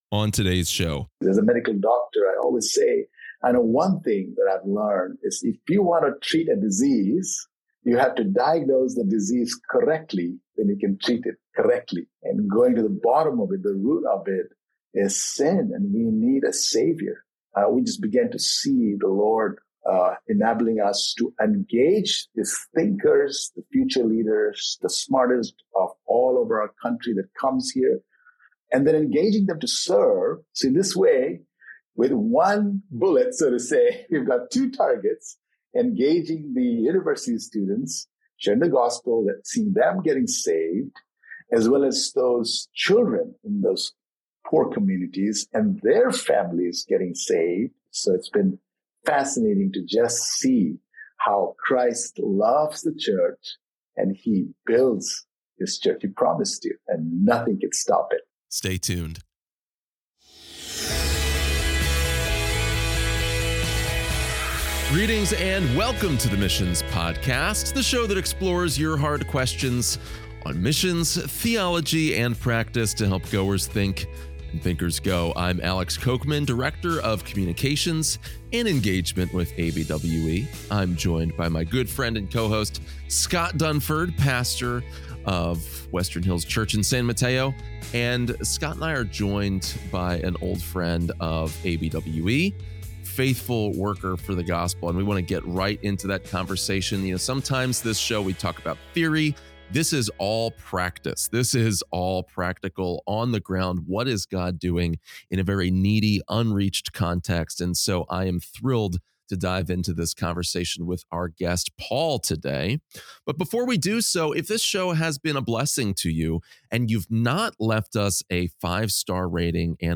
are joined by a Live Global ministry partner working in South Asia. This partner details the many ways that his for-profit and non-profit businesses are engaging people from the richest and highest in society to the very lowest. Together they talk about ministry to Hindus and the need for a centralized place to do ministry in the most populous city in the world.